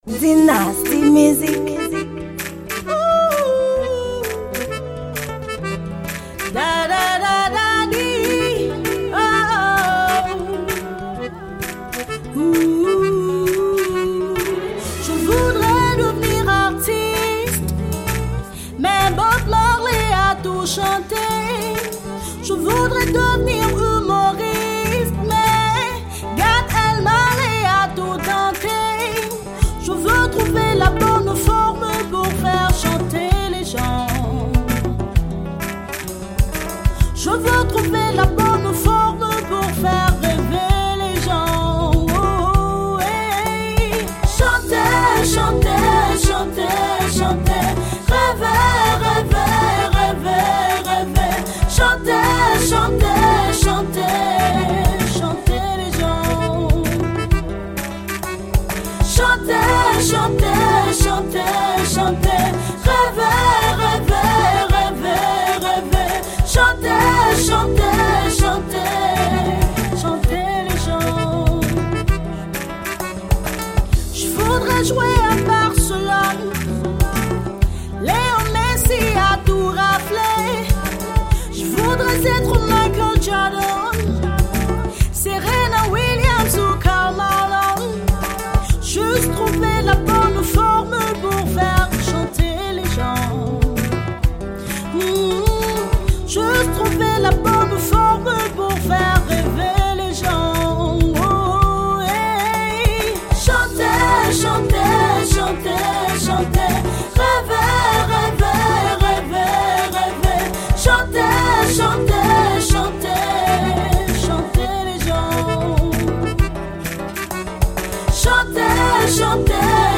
Genre: Classique.